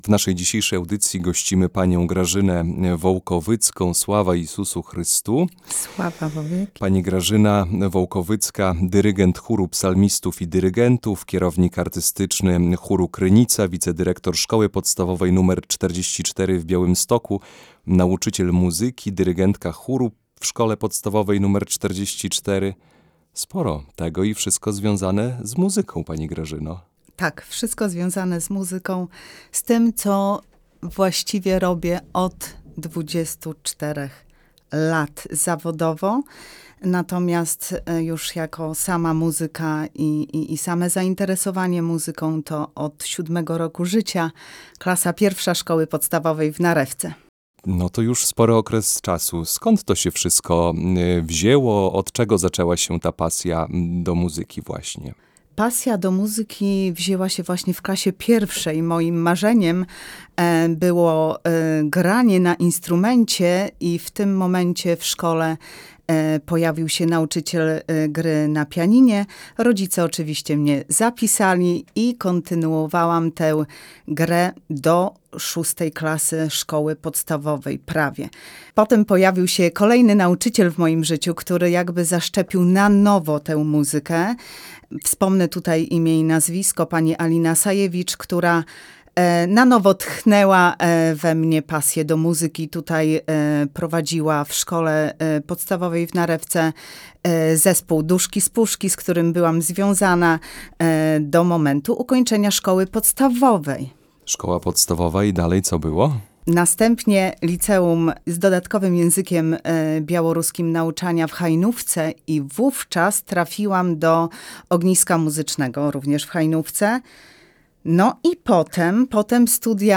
Pasja do muzyki i pracy z chórem – rozmowa